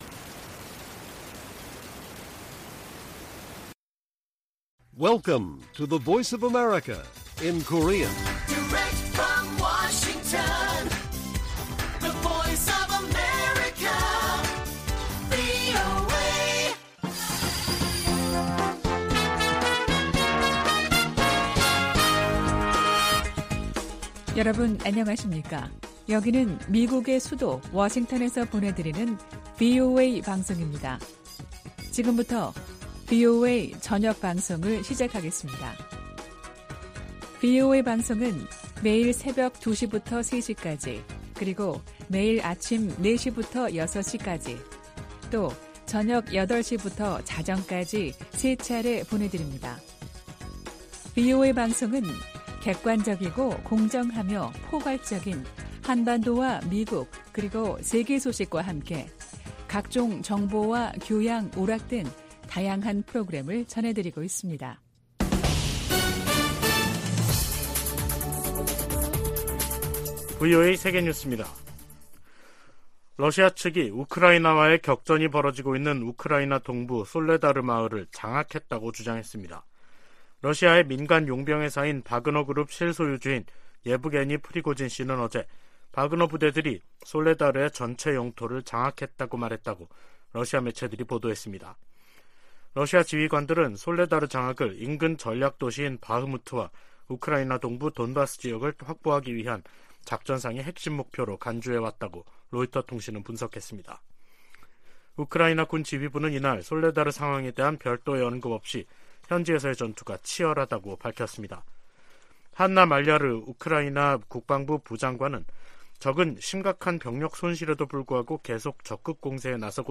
VOA 한국어 간판 뉴스 프로그램 '뉴스 투데이', 2023년 1월 11일 1부 방송입니다. 윤석열 한국 대통령은 북한의 잇단 도발 행위들은 한국의 대응 능력을 강화하고, 미한일 간 안보 협력을 강화하는 결과를 가져올 것이라고 말했습니다. 미국과 한국은 다음달 북한의 핵 공격 시나리오를 가정한 확장억제수단 운용연습을 실시합니다.